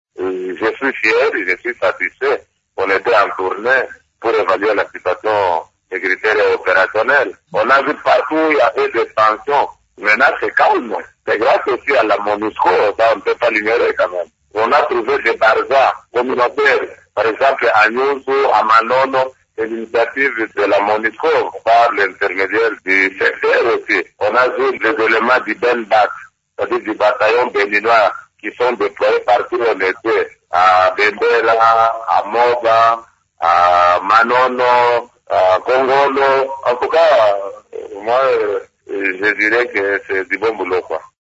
Dans cet extrait sonore, le commandant de la 2eme zone de défense des FARDC reconnaît que la présence des éléments du bataillon béninois de la Monusco a beaucoup contribué à la pacification de cette zone: